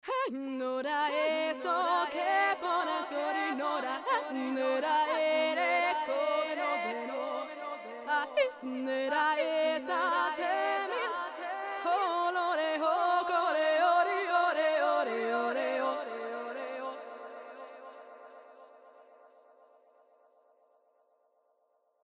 Uhbik-D – Delay
Uhbik-D liefert einen überzeugenden, warmen Klang, der nach Bandecho klingt und den man mittels Kuhschwanzfilter für die Absenkung von Bässen und Höhen einengen kann.
„Horseback“ mit dem bereits bekannten Gesang: